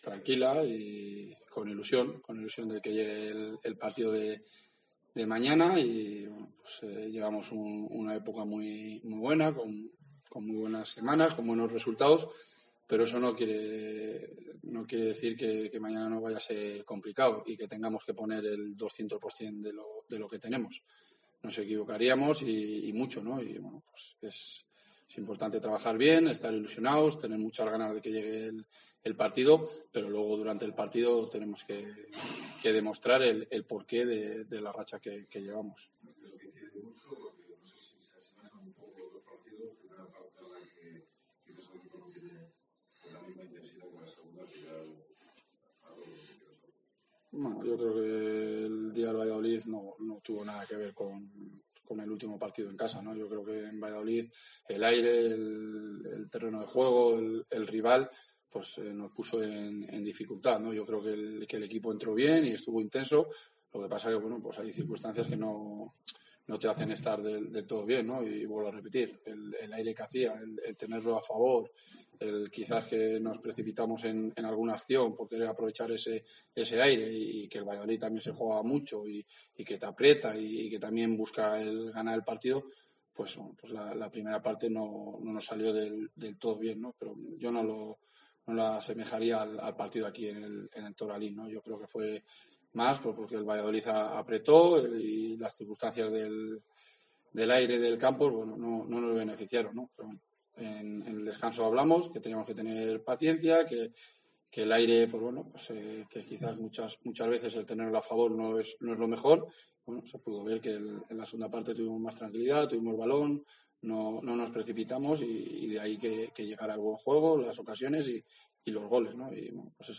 Escucha aquí las palabras del míster de la Deportiva Ponferradina